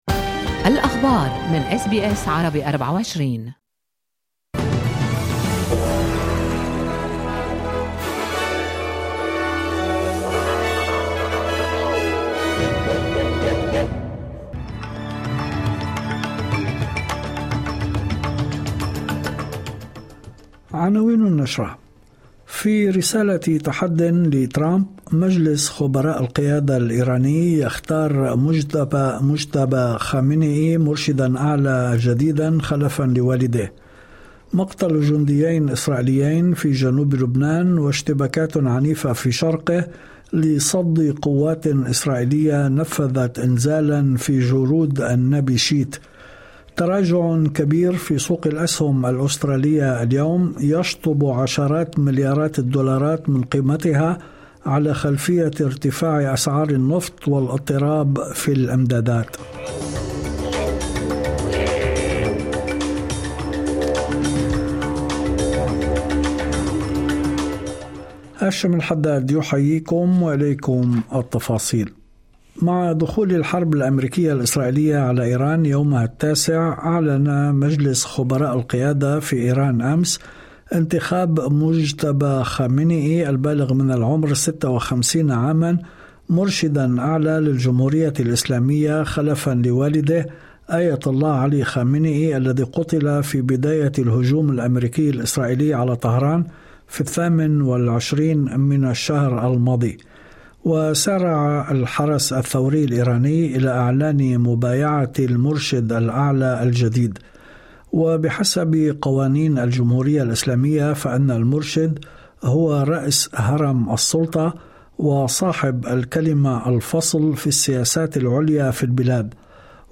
نشرة أخبار المساء 09/03/2026